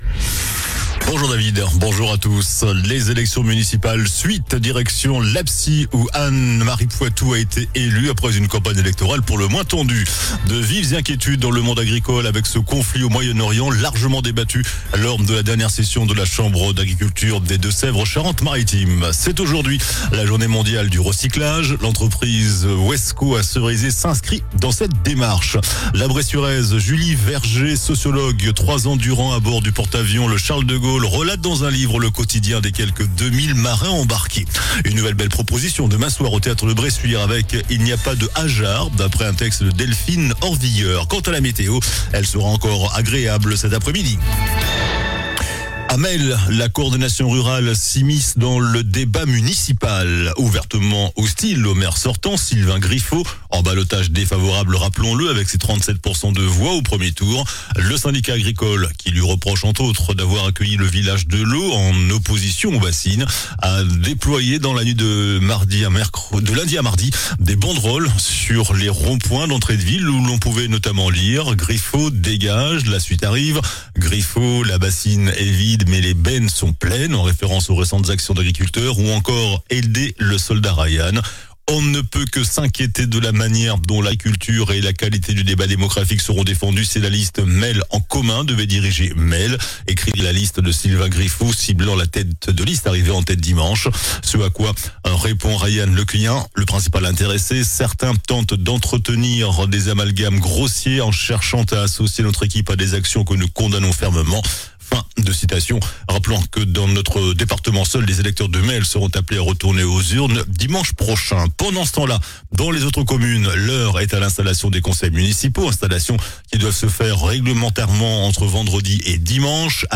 JOURNAL DU MERCREDI 18 MARS ( MIDI )